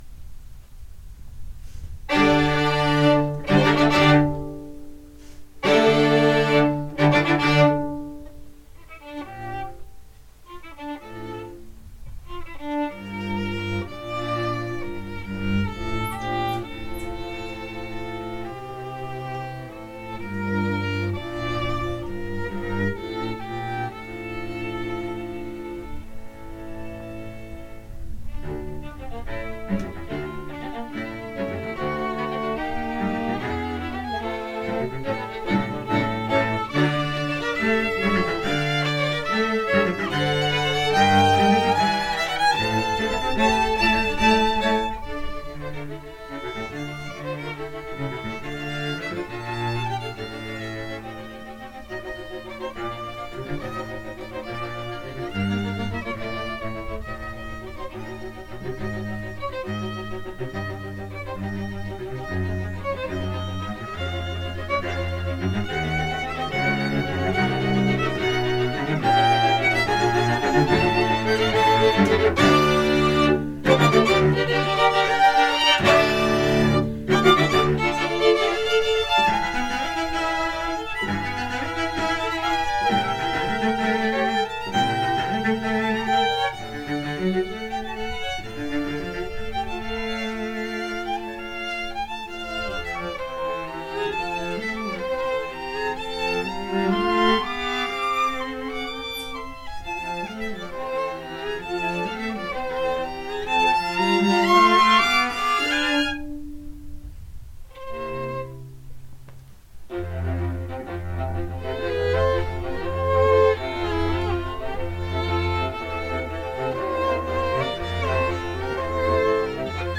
Chamber, Choral & Orchestral Music
Chamber Groups